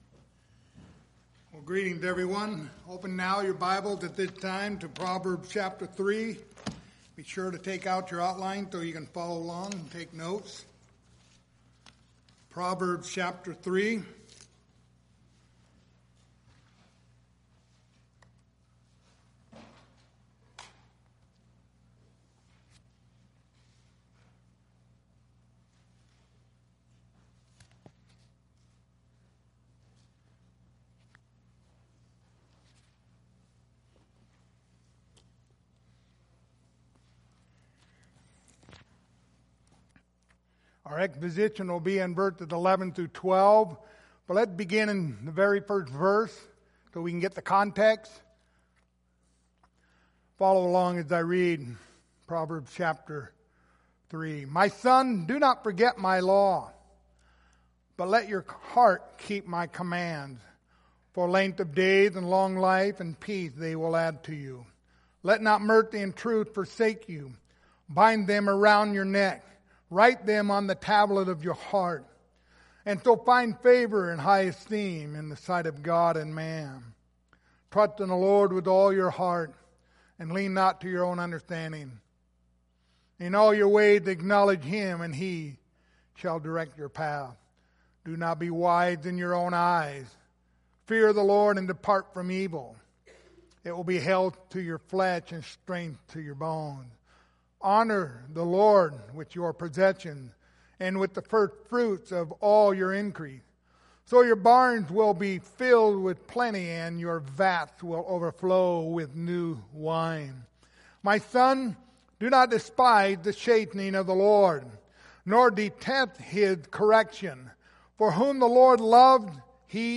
The Book of Proverbs Passage: Proverbs 3:11-12 Service Type: Sunday Morning Topics